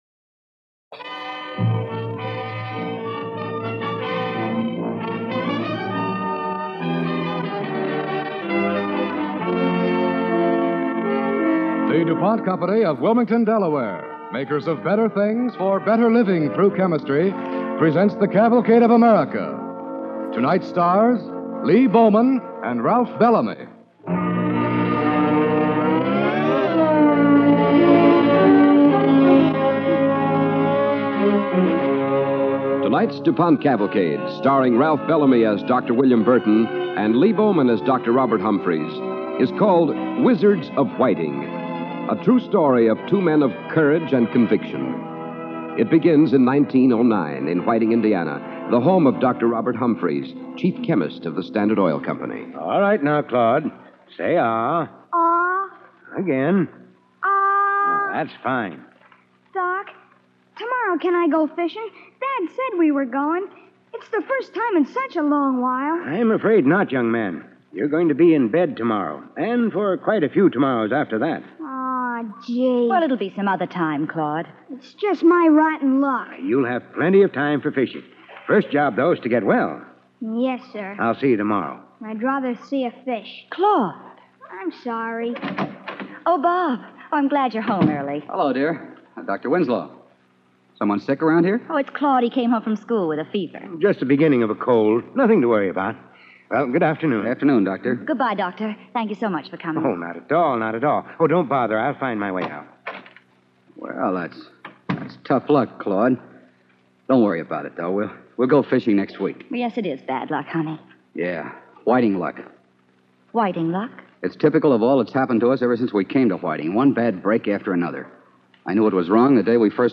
The Wizards of Whiting, starring Ralph Bellamy and Lee Bowman